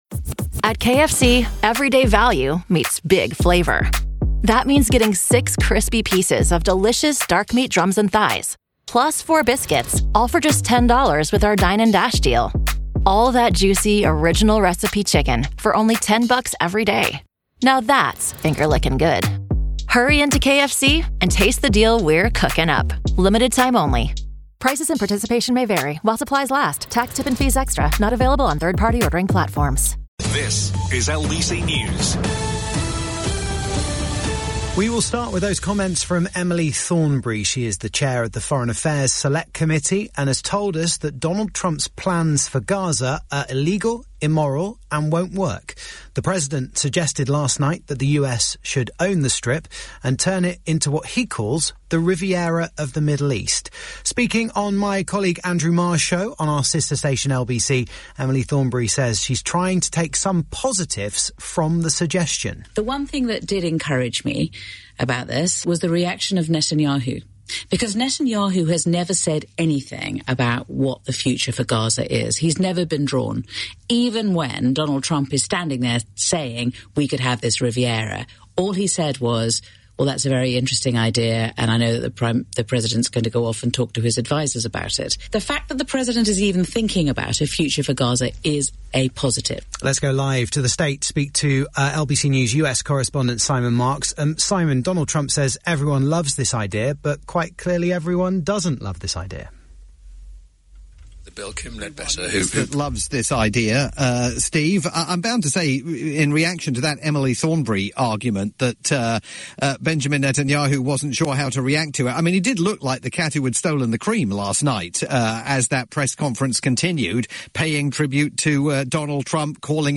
live update from Washington for the UK's only rolling news station, LBC News.